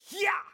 ※音量注意のため、音小さめにレンダリングしてあります
前奏の後、長い長い間奏があるんですが、何と驚きの40小節！！
でも、そのタメにタメた後のかっこいいサビが堪りません。笑